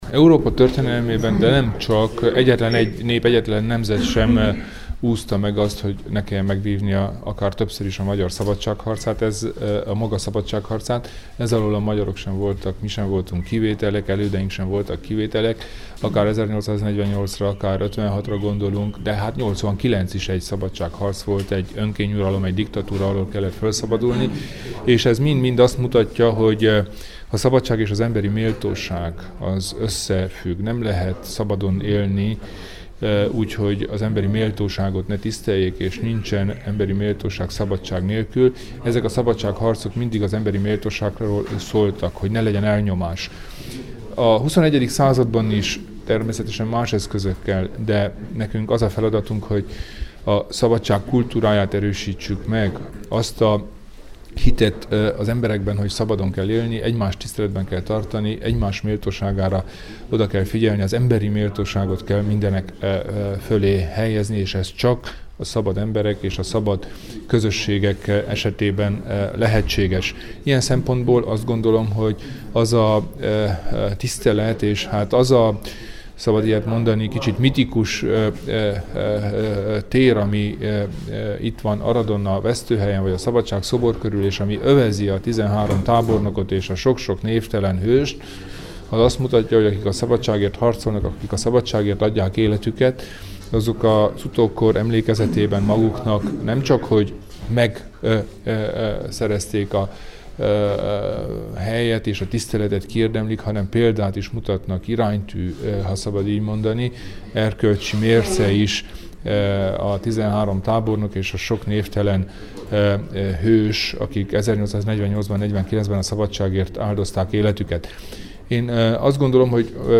Az 1848–1849-es magyar forradalom és szabadságharc véres megtorlásának, a 13 vértanú honvédtiszt aradi kivégzésének 167. évfordulóján rendezett gyászünnepség alkalmából az aradi RMDSZ-székházban tartott sajtótájékoztatót csütörtök reggel Kelemen Hunor, a szövetség elnöke.